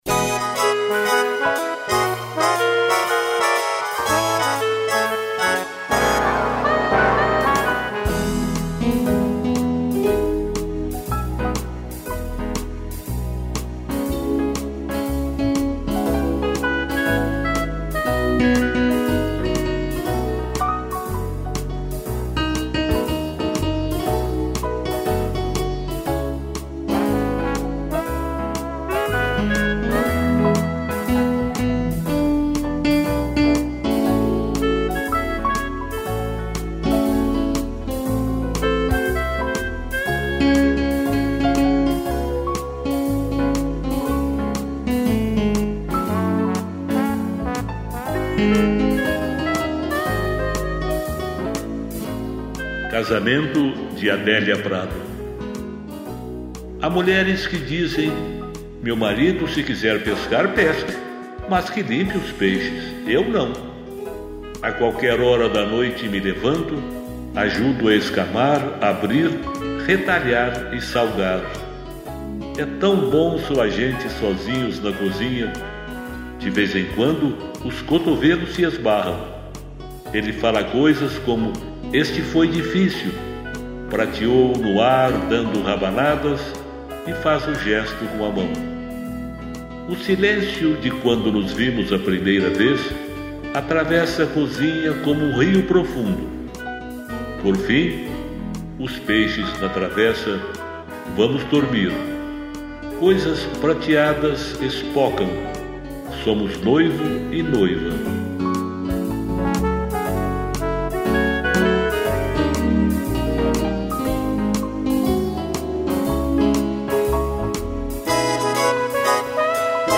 piano, clarineta e trombone